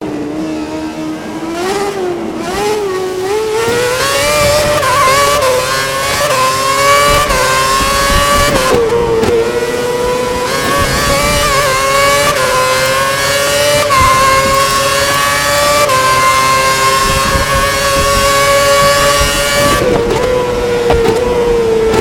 Звуки Формулы-1
Звук ревущего мотора Формулы 1 из кокпита